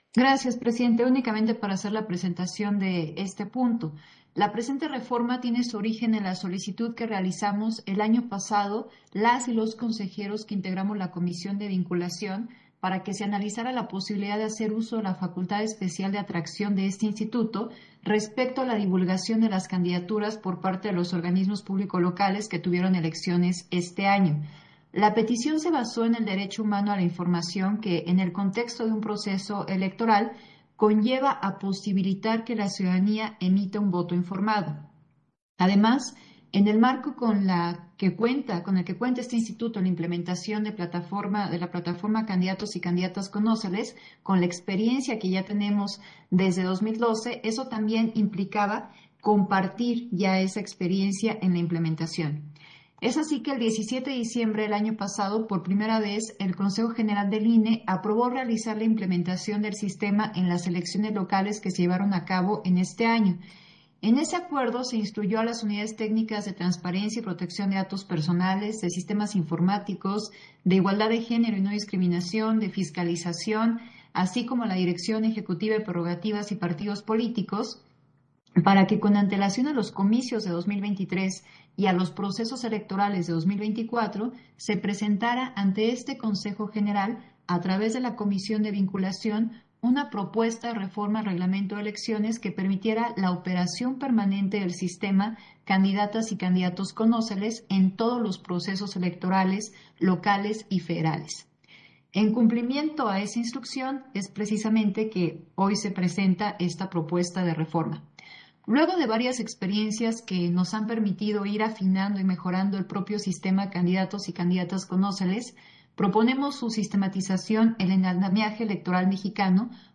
Intervención de Dania Ravel, en el punto 12 de la sesión extraordinaria, por el que acuerdan las modificaciones al reglamento de elecciones del INE